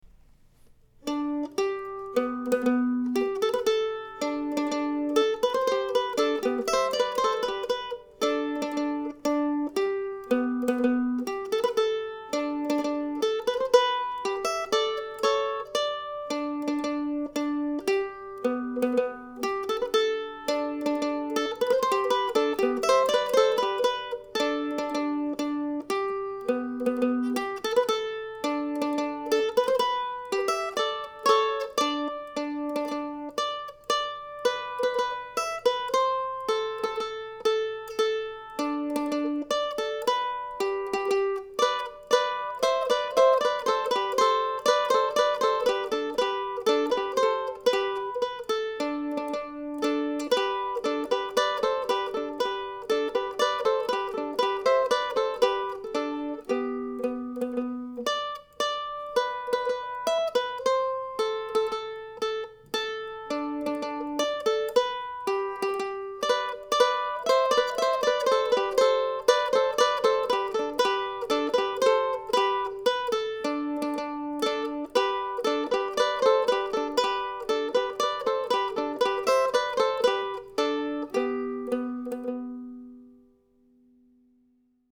I used this mando for this week's recordings as well, along with a new set of Thomastik strings.